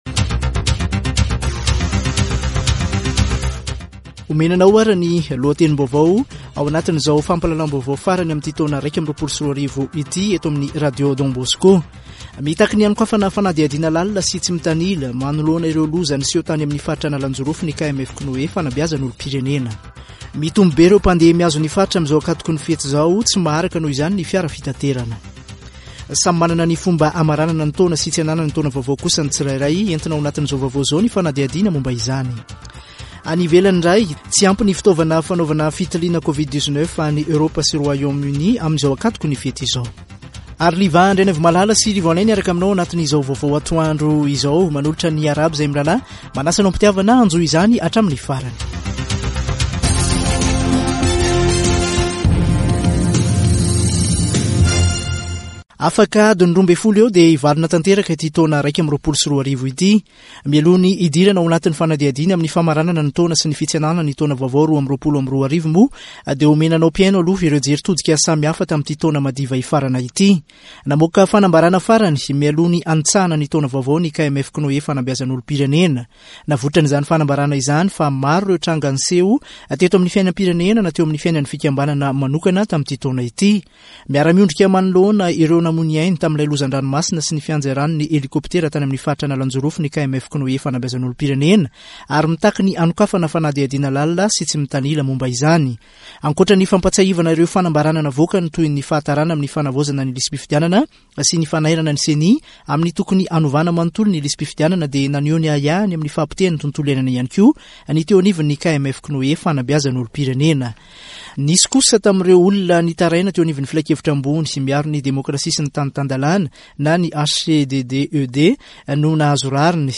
[Vaovao antoandro] Zoma 31 desambra 2021